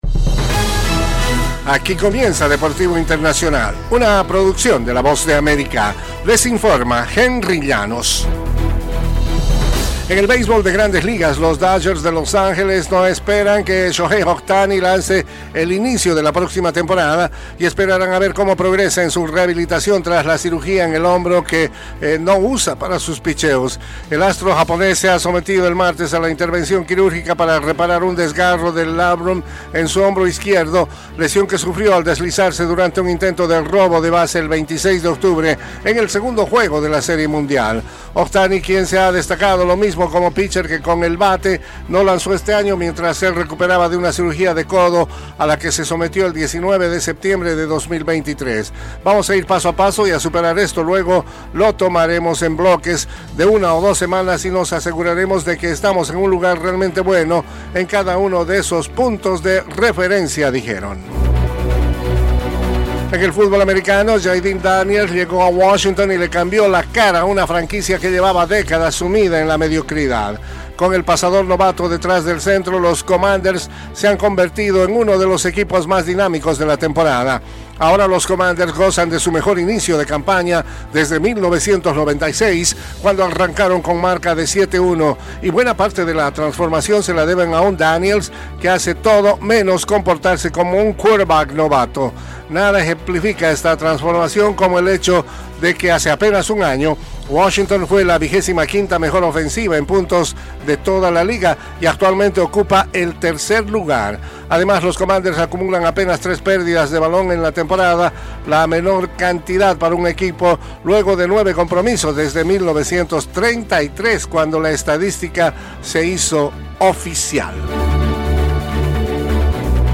Las noticias deportivas llegan desde los estudios de la Voz de América